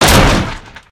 wood_crash1_hl2.ogg